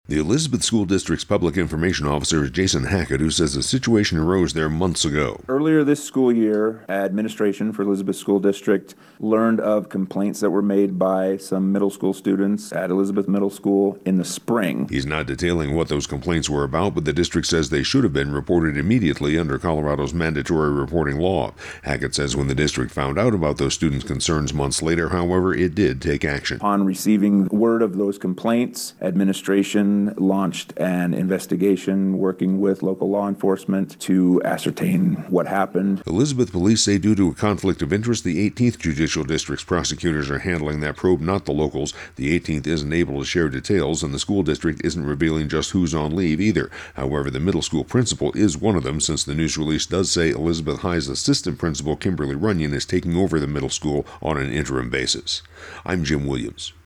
(Elizbeth Schools Probe wrap                         :57)